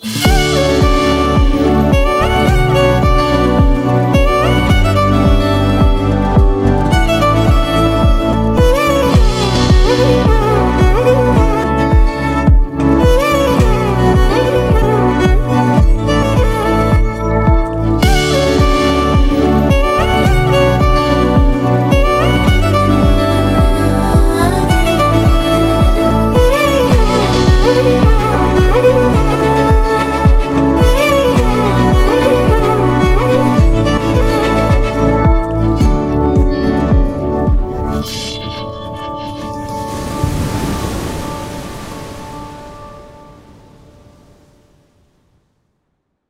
without dialogues and disturbances
violin BGM